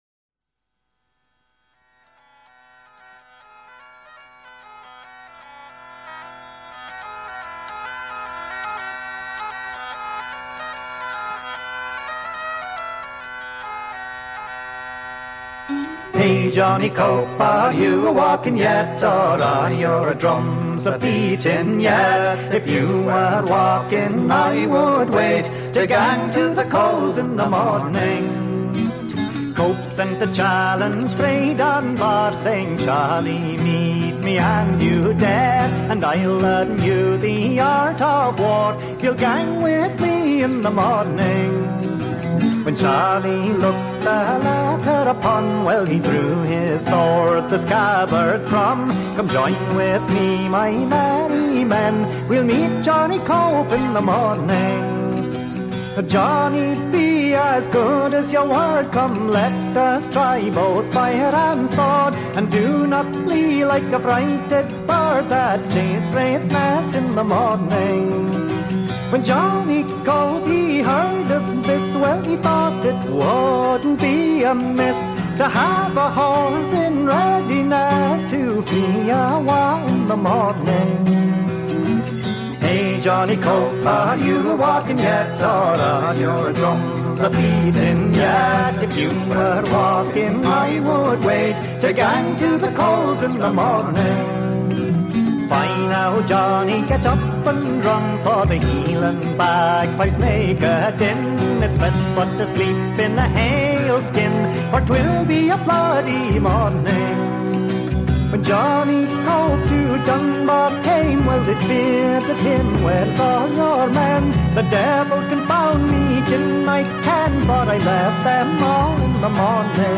epic six-part